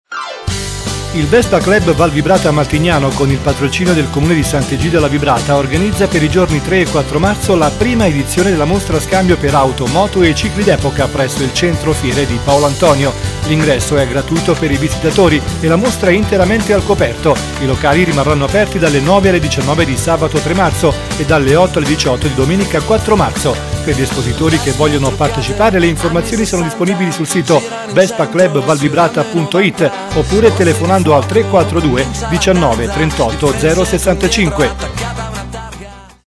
Spot della mostra
Spot su lattemiele